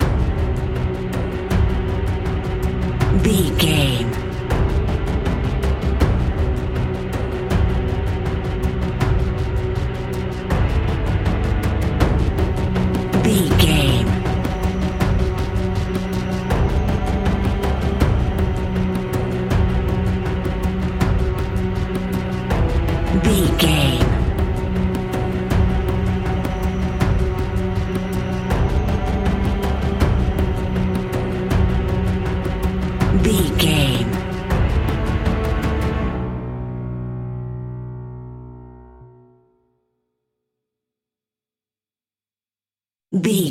Aeolian/Minor
ominous
dark
eerie
drums
synthesiser
piano
strings
horror music